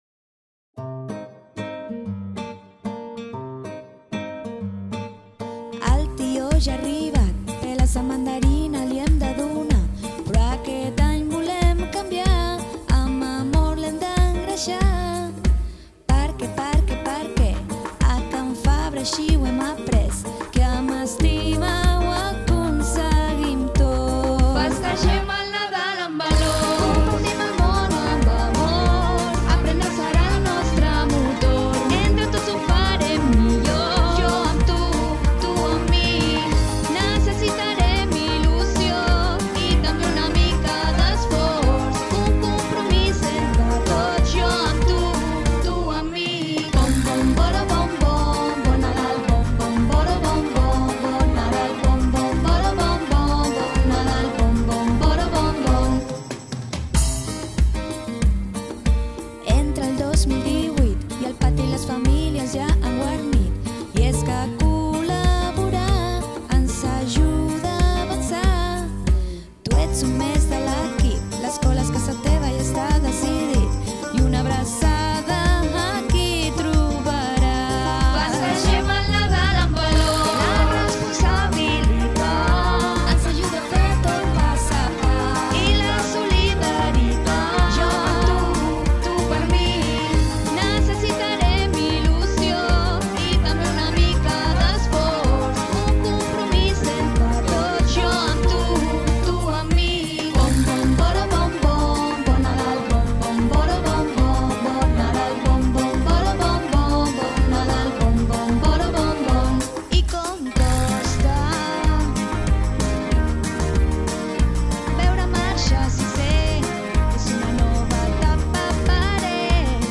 LA NADALA COMPARTIDA D´AQUEST ANY A CAN FABRA